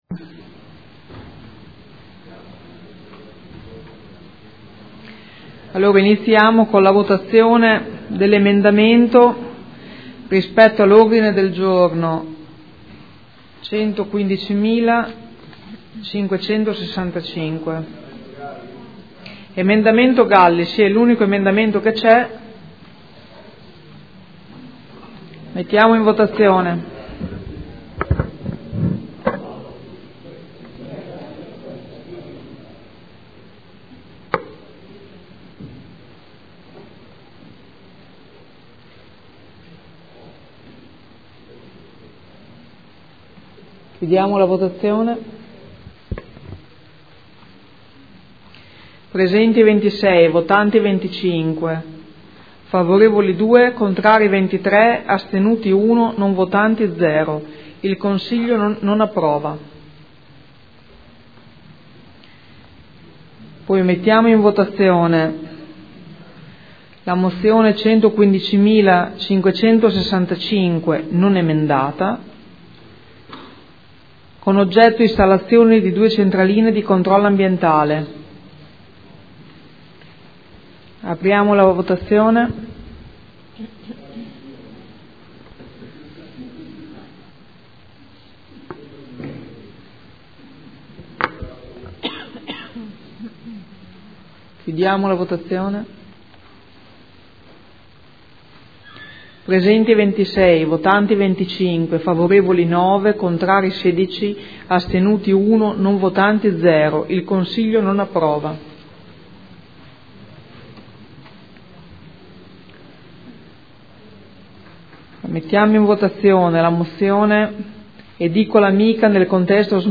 Presidente — Sito Audio Consiglio Comunale